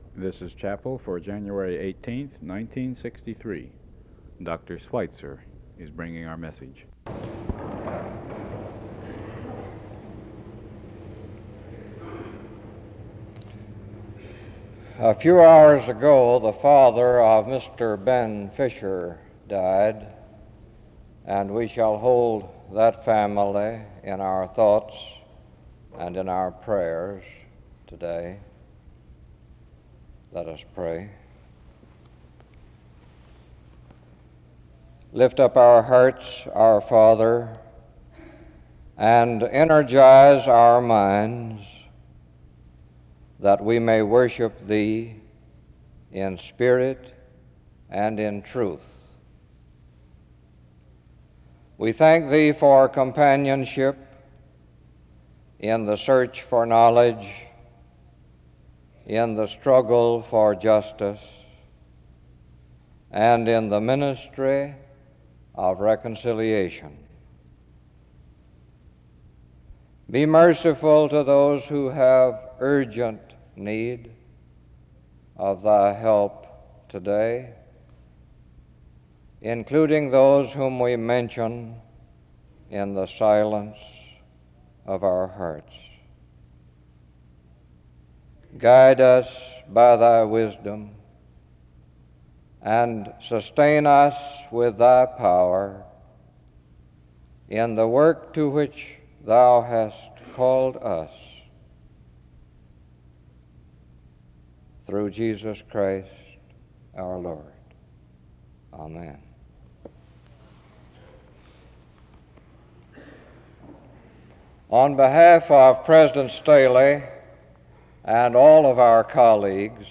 The service begins with prayer from 0:00-1:49. An introduction to the speaker is given from 1:54-3:34. Dr. Schweizer gives a message from 3:40-43:02. Schweizer gives a biblical interpretation of 1 Corinthians 12. He focus primarily on the spirit of God and its work in believers today.